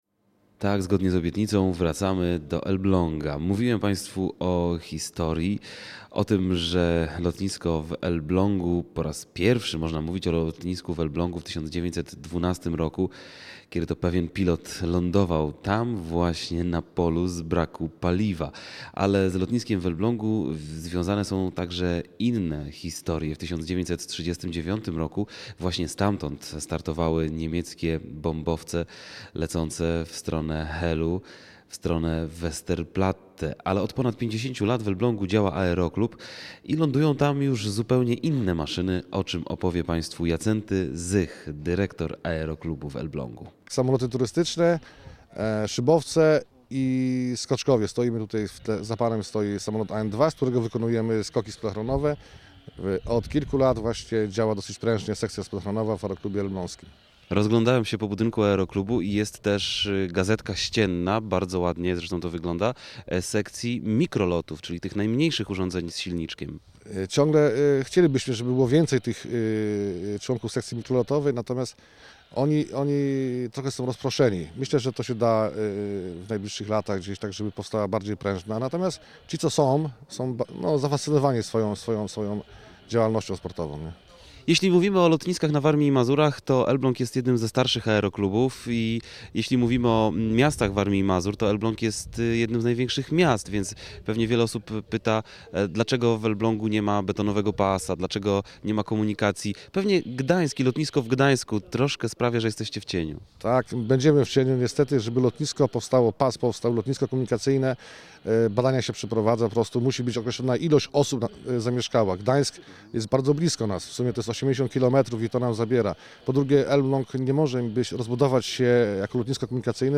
2009-06-13Relacja z Rajdu po Lotniskach Warmii i Mazur - Elbląg, cz. 2 (źródło: Radio Olsztyn)